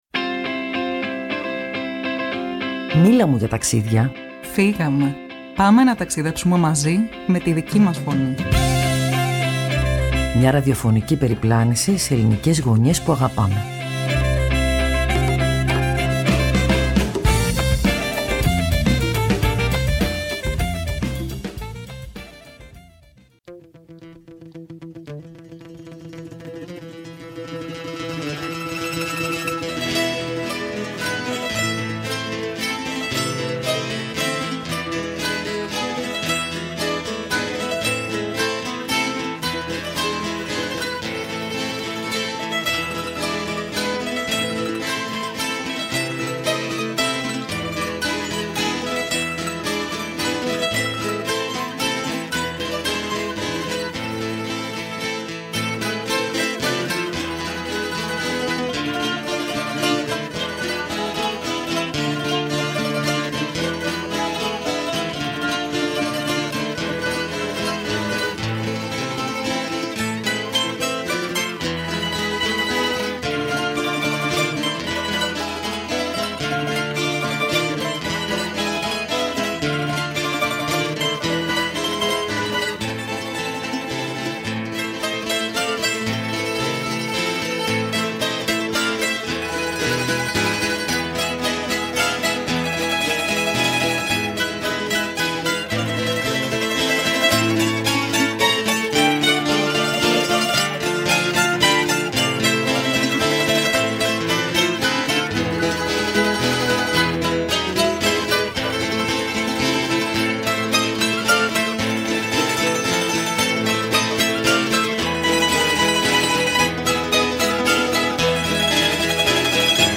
Ο Αντιδήμαρχος Πολιτισμού Τάκης Βρυώνης, με εμπειρία δεκαετιών στα ΜΜΕ και την τοπική πολιτιστική ζωή, περιέγραψε έναν τόπο που συνεχίζει να δημιουργεί, να τραγουδά και να αντιστέκεται στην ισοπέδωση.
Με φόντο ζακυνθινές μπαλάντες, το ραδιόφωνο έγινε γέφυρα ανάμεσα στο χθες και το αύριο.